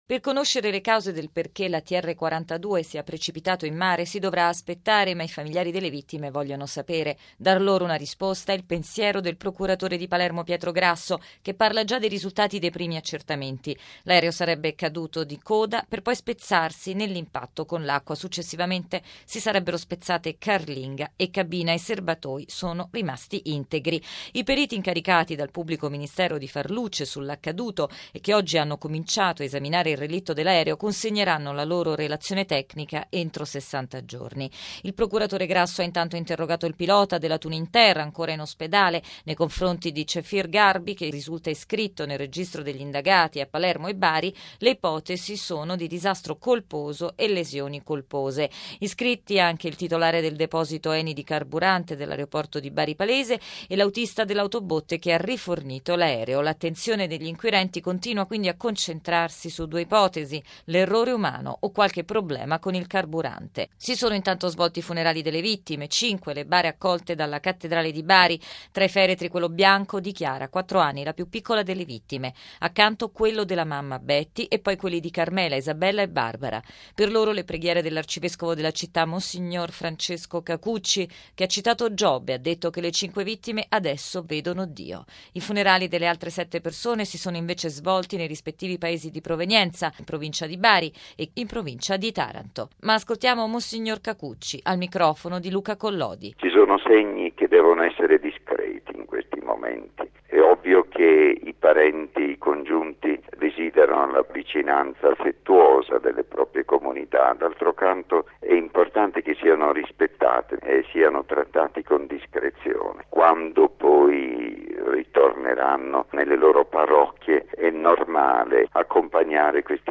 Intanto sono tre gli indagati dalla procura di Palermo, che ha anche nominato un collegio di periti. Servizio